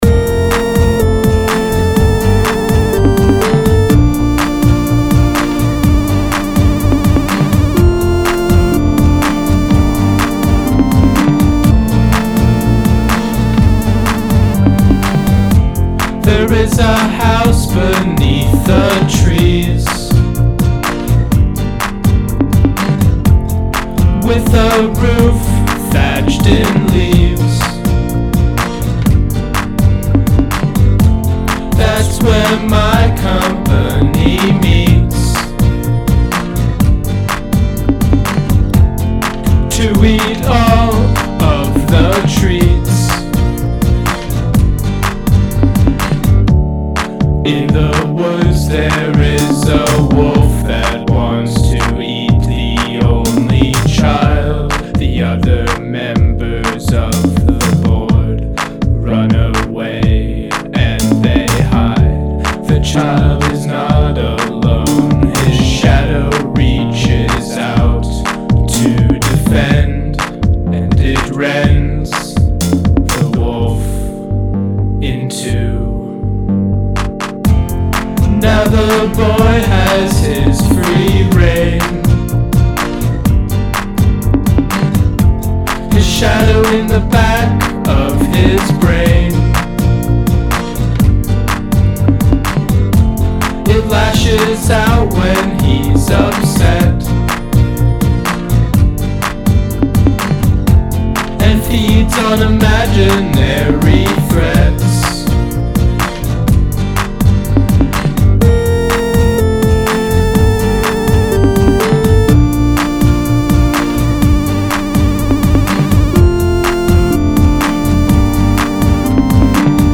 This song is also a departure from completely electronic music.
I layered some vocals for this piece.